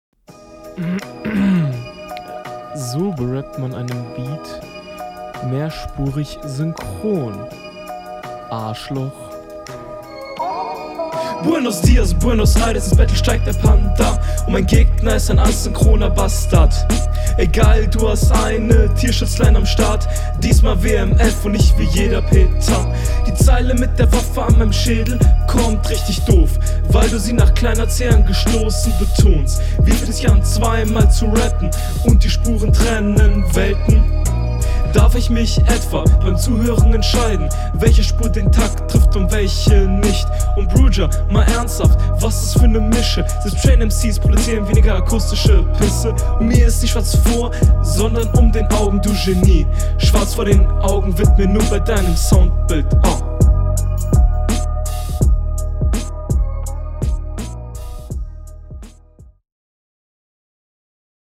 Das ist in der Tat mehrspurig synchron, aber nicht so dolle gerappt.